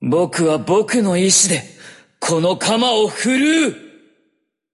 Voice Actor Akira Ishida
Voice Lines